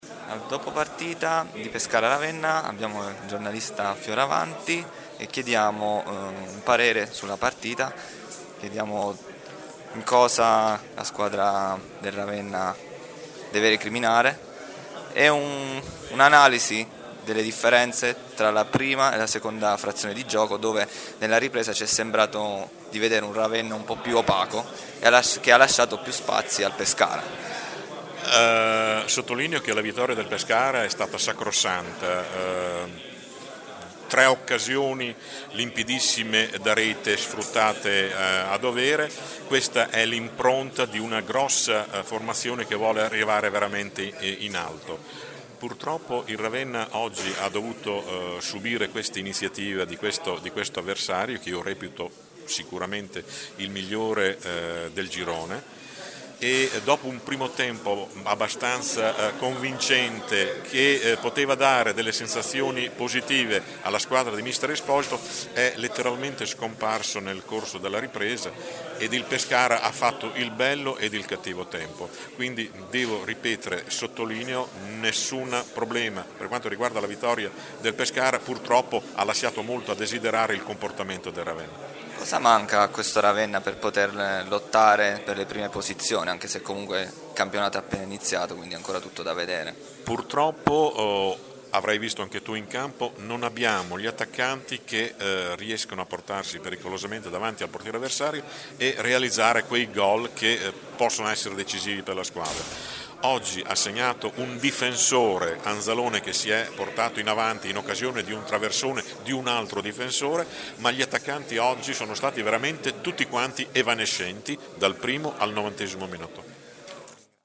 Interviste dopo partita: Pescara-Ravenna